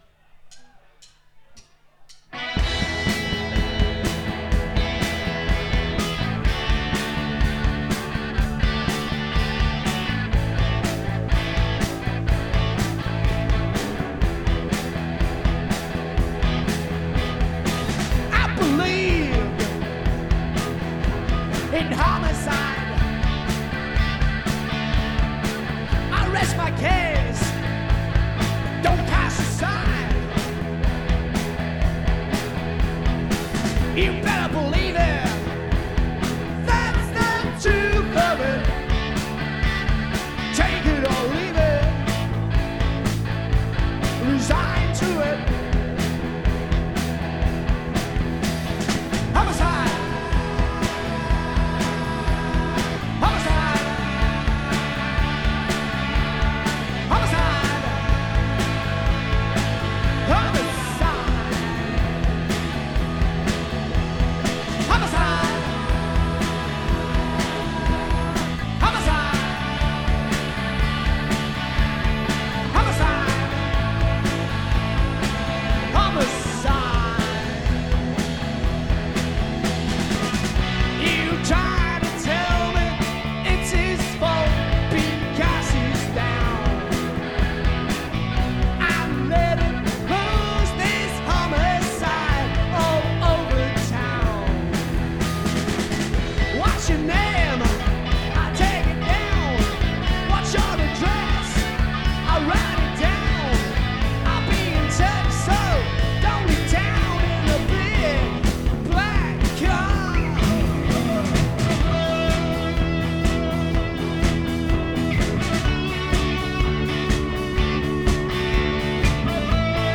This is a great, neglected punk classic.
Except that my backing vocals are a bit out of tune.